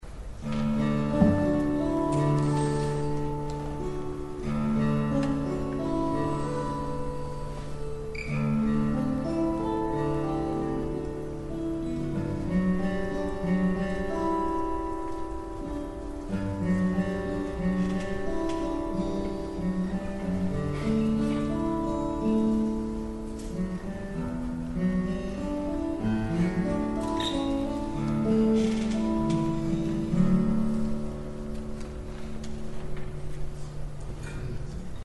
These are audio clips from the 2011 convention workshop.
4-1/2-year-old guitar has softer Adirondack top and braces is deeper and warmer, but without much sparkle.